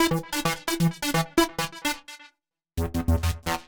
Index of /musicradar/uk-garage-samples/130bpm Lines n Loops/Synths